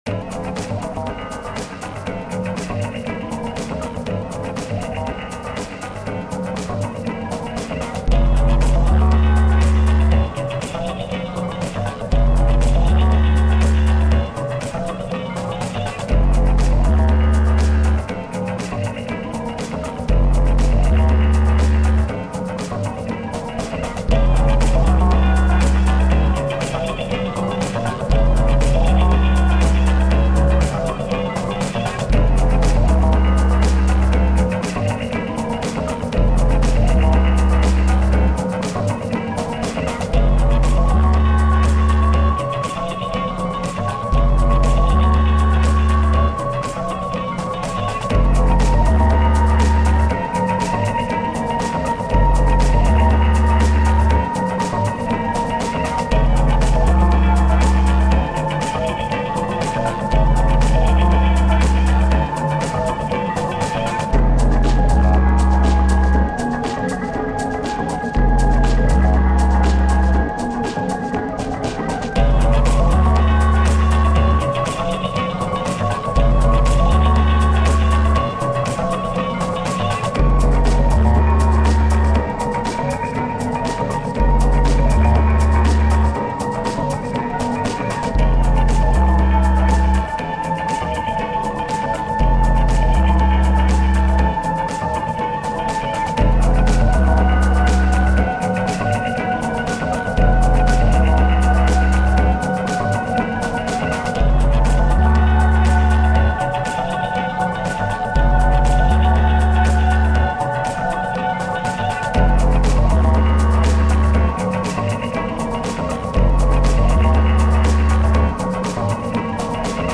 Danza 1,” here excerpted, is an instrumental sketch for another of the Jonestown Totentanzes.